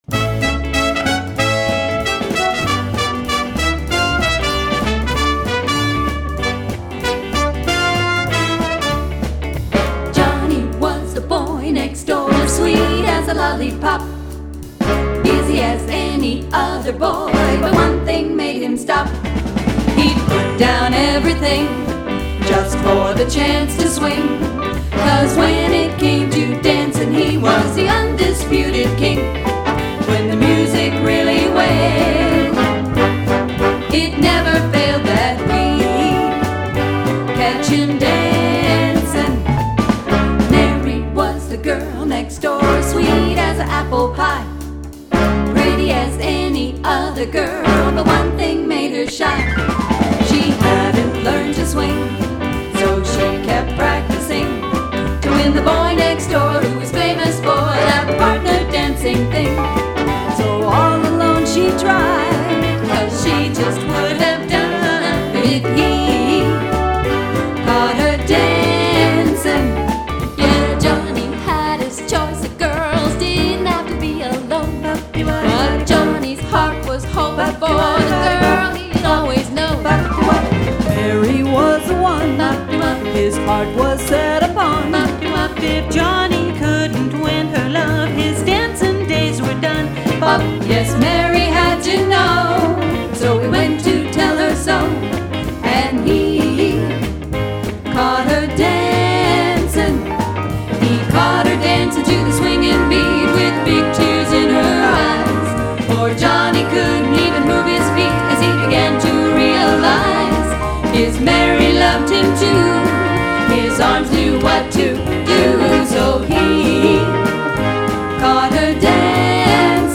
vocal trio